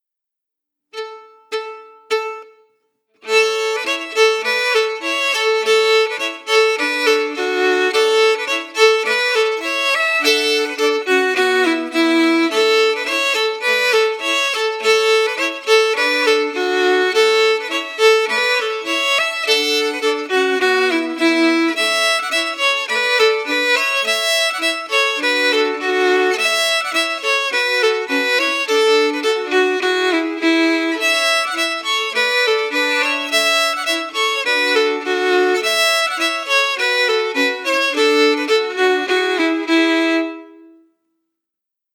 Key: A
Form: Polka
Melody emphasis
Region: Ireland